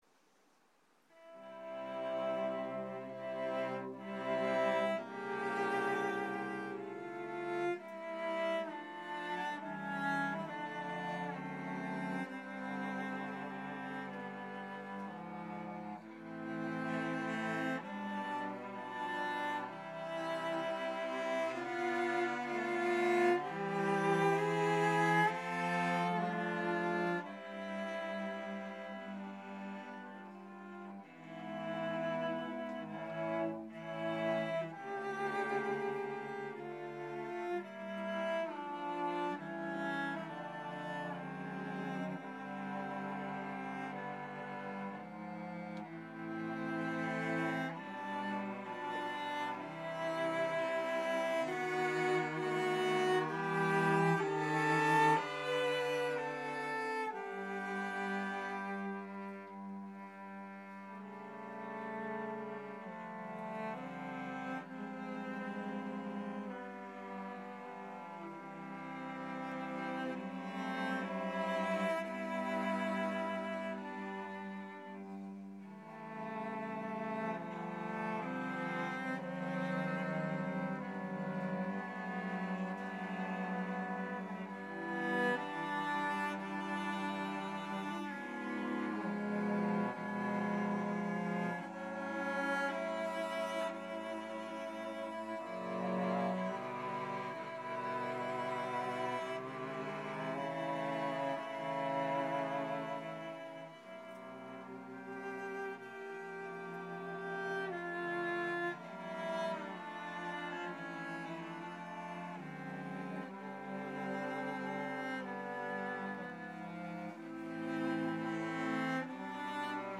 Chamber, Choral & Orchestral Music
2cello quartet.mp3